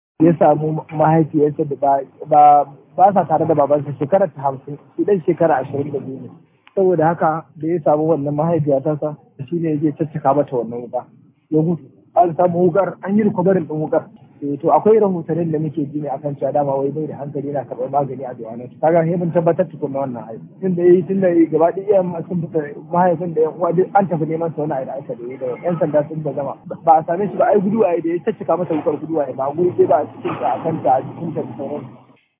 A zantawarsa da Freedom Radio
Latsa alamar Play domin sauraron muryarsa.